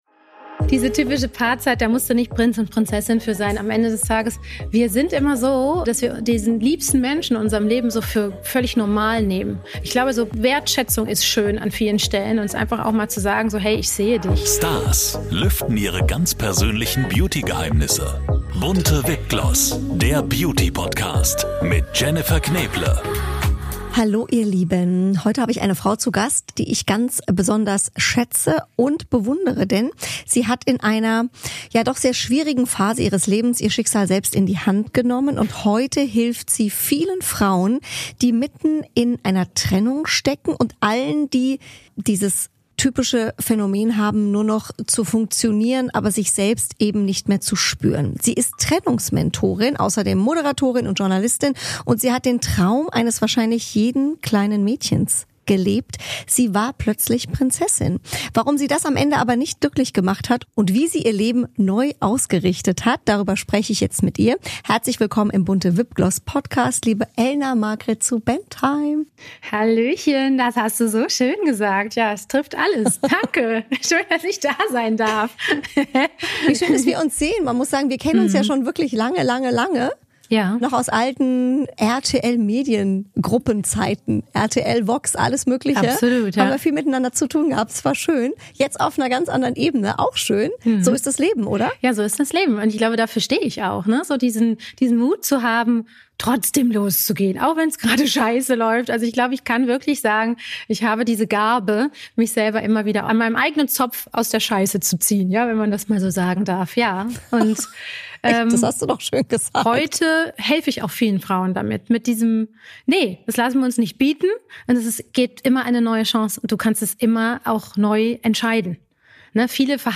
Ein ehrliches, inspirierendes Gespräch über Liebe in allen Facetten und den Mut zum Neubeginn